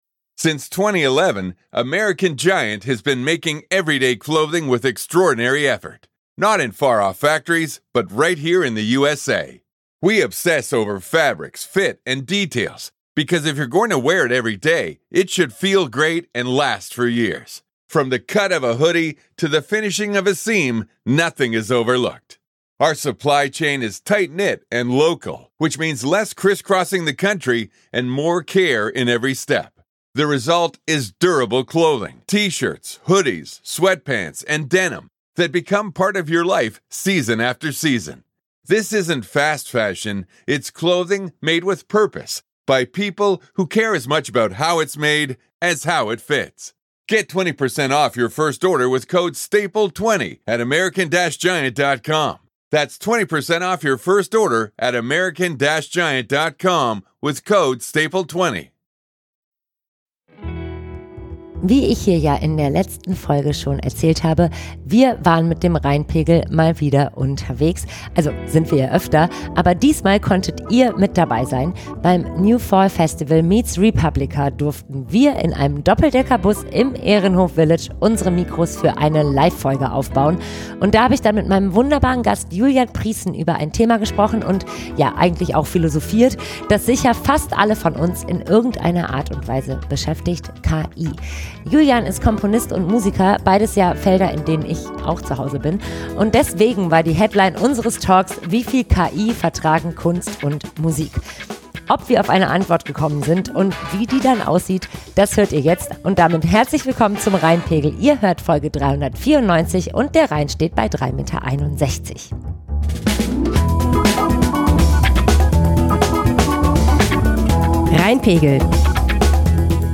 Rheinpegel Live beim New Fall Festival. Das Thema: Wie viel KI vertragen Kunst und Musik?